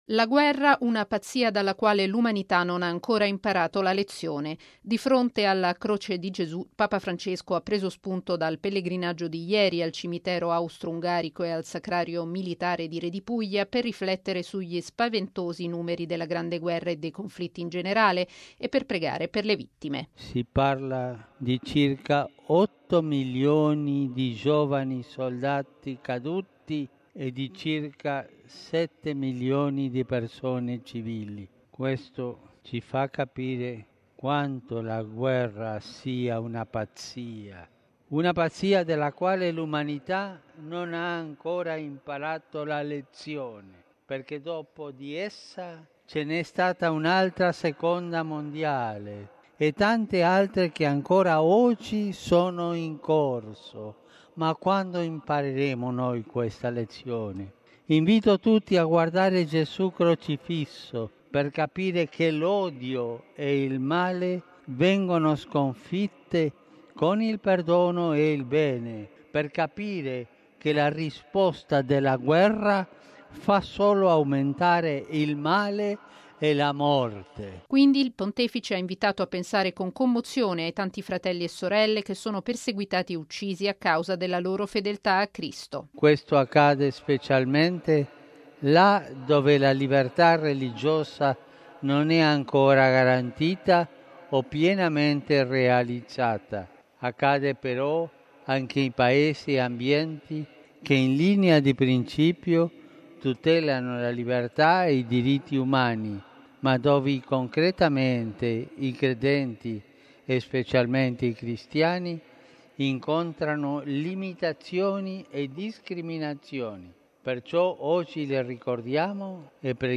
Nell’odierna festa dell’Esaltazione della Santa Croce, il pensiero del Papa all’Angelus in Piazza San Pietro è andato ai cristiani perseguitati per la loro fede e, subito dopo, alle vittime di tutte le guerre, ricordando il pellegrinaggio di ieri a Redipuglia, e alla Repubblica Centroafricana, dove domani avrà inizio una missione di pace dell’Onu.